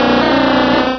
Cri de Lamantine dans Pokémon Rubis et Saphir.